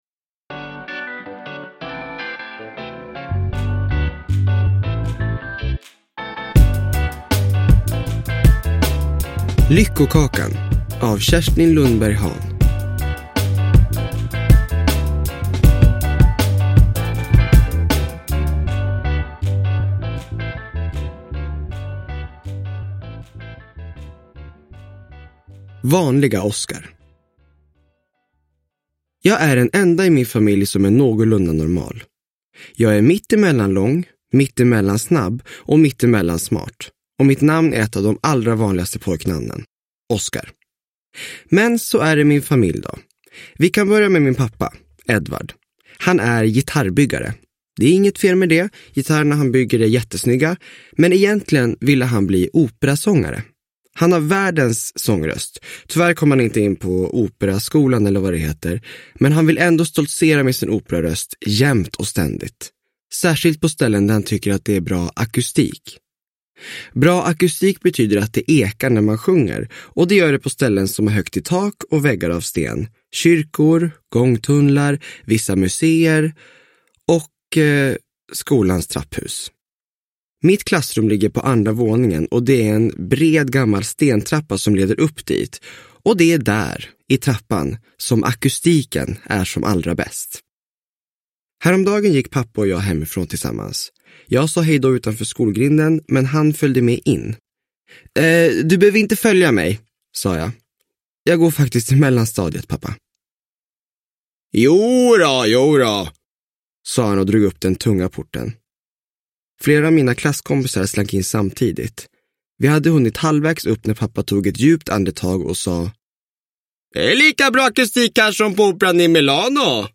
Lyckokakan – Ljudbok – Laddas ner
Uppläsare: William Spetz